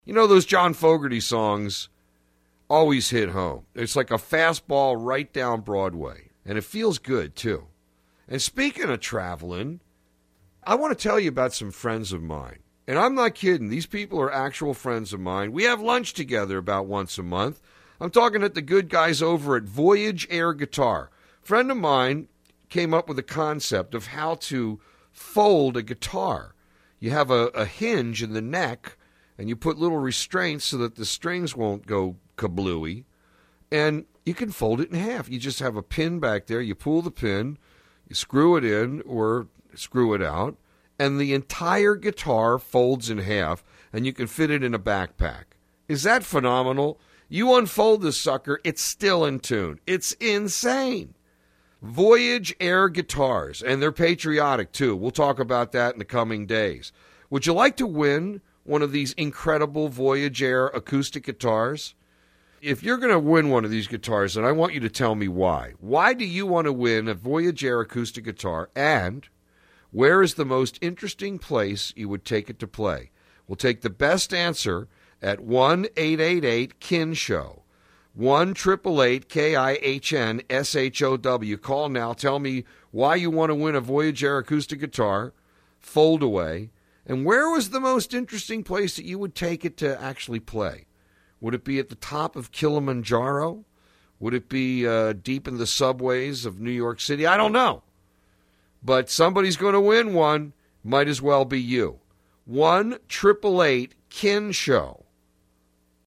greg_kihn_interview.mp3